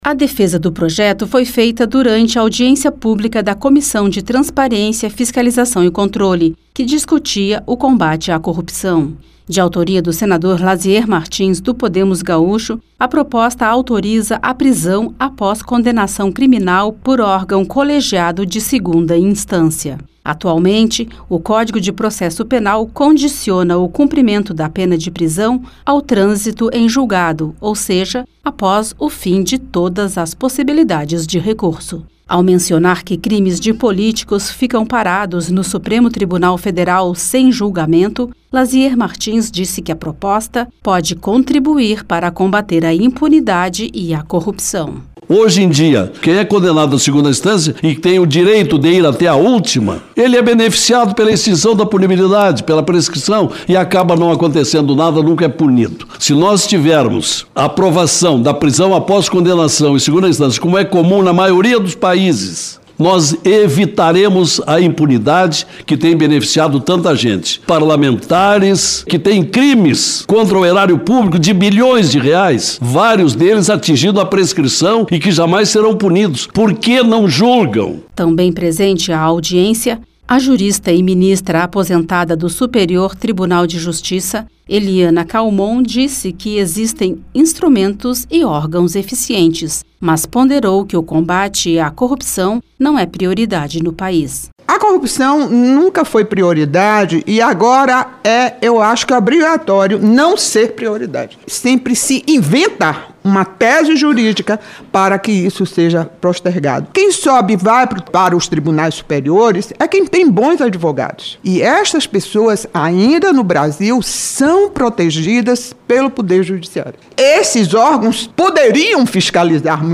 Eles participaram de audiência pública na Comissão de Transparência, Governança, Fiscalização e Controle e Defesa do Consumidor (CTFC).